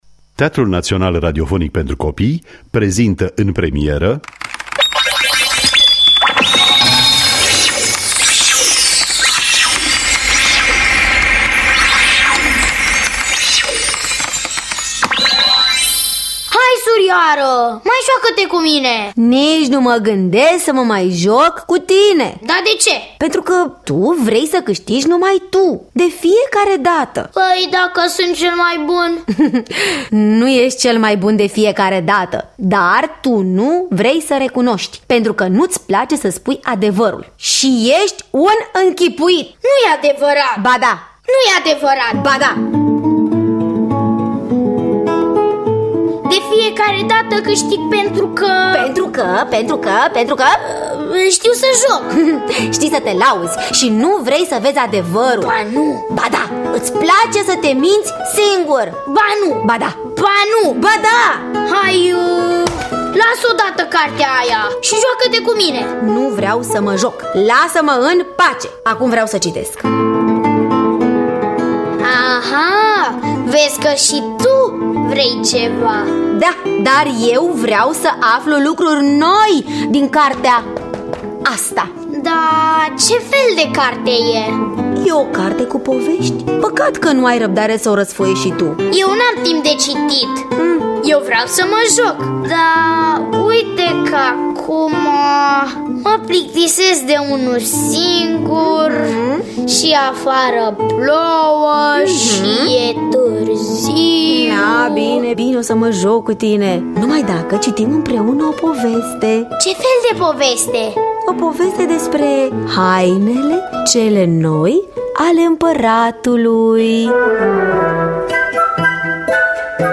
Teatru Radiofonic Online